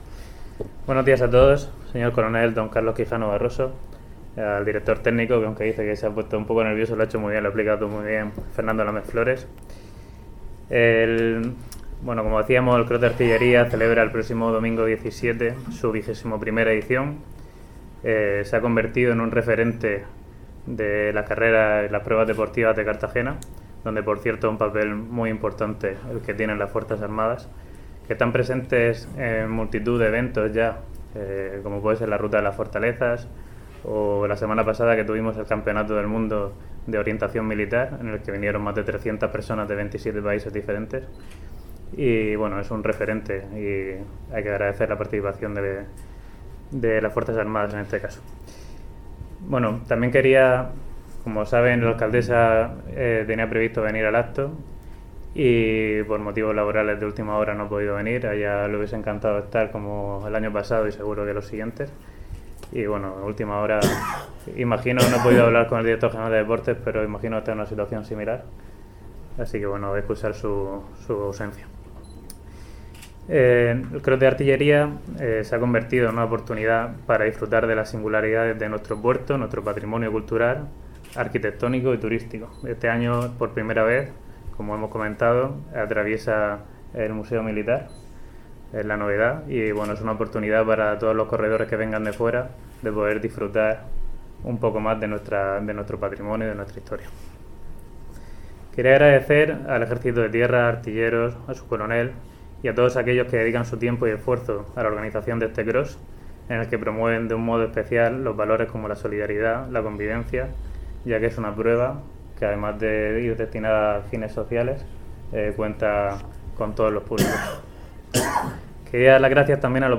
Enlace a Declaraciones del concejal de Deportes, José Martínez